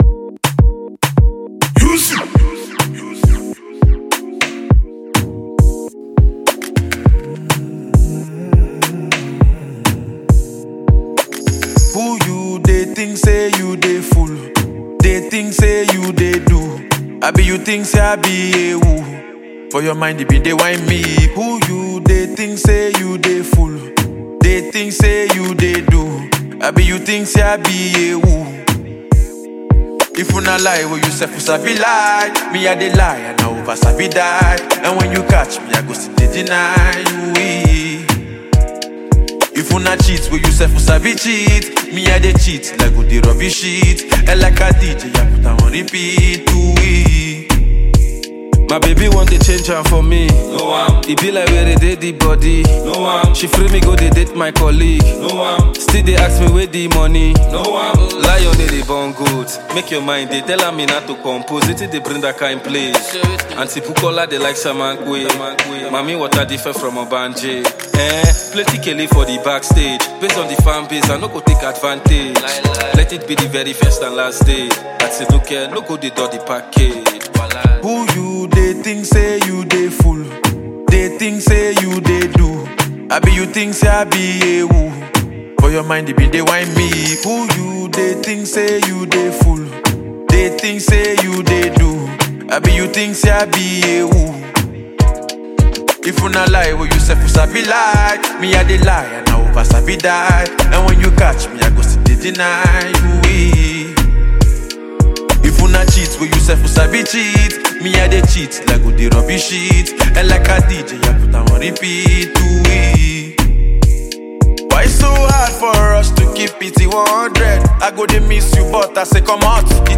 Popular Nigerian music duo and songwriters